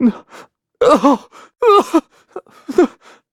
Kibera-Vox_Sad.wav